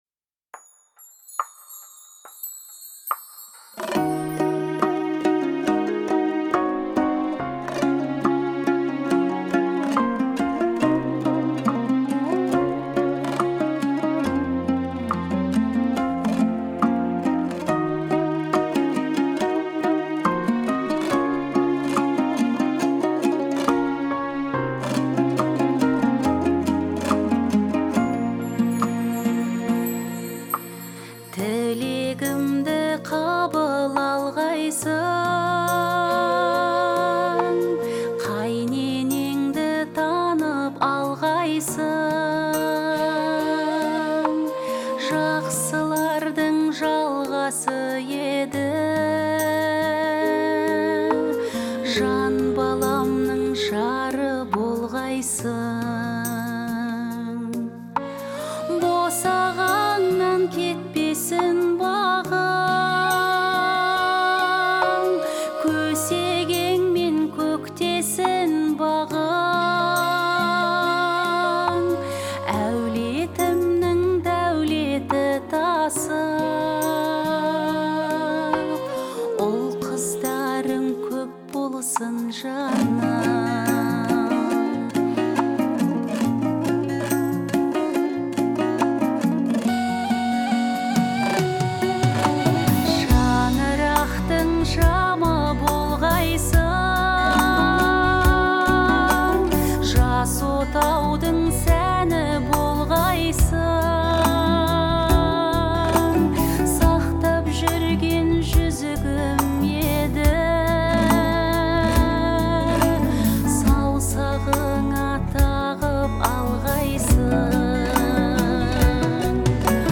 это трогательное произведение в жанре поп
обладая сильным голосом